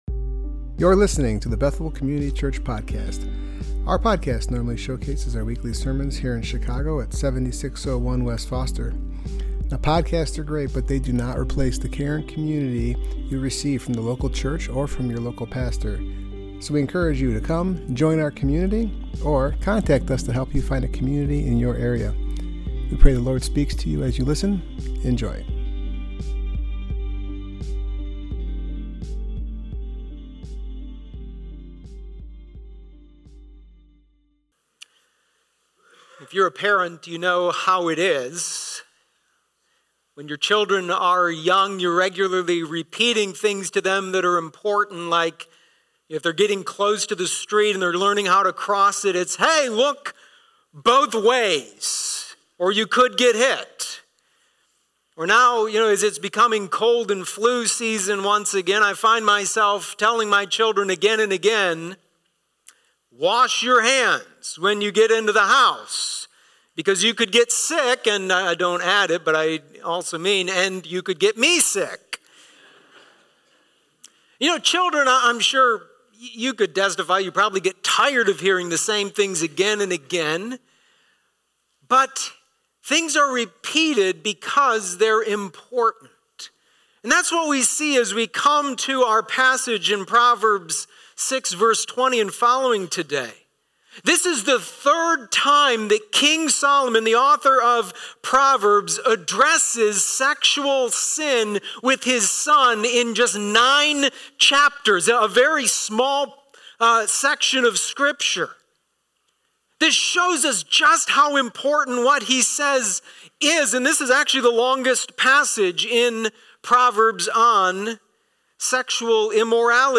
Service Type: Worship Gathering